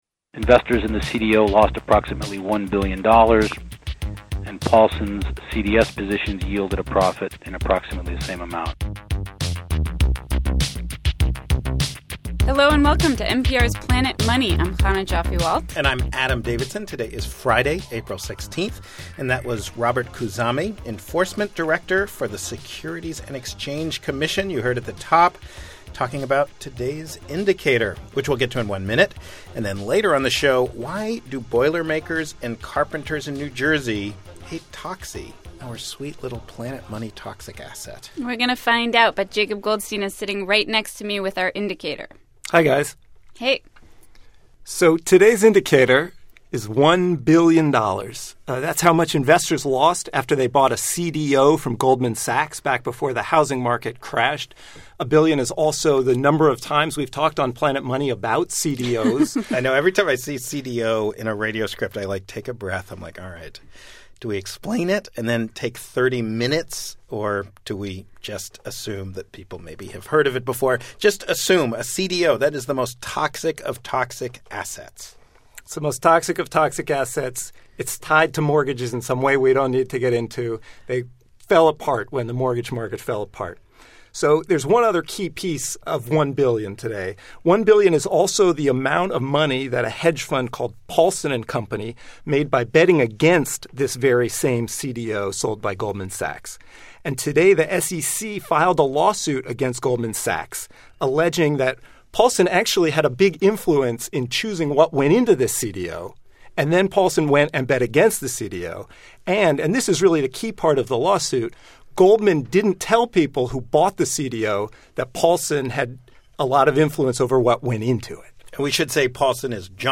And a New Jersey carpenters' union fund that bought a piece of the asset tries to figure out if there's someone to blame. On today's Planet Money, we hear from a carpenter, a lawyer and a guy who owns a pub in London. Oddly enough, the pub owner could be the one bailing the carpenters out.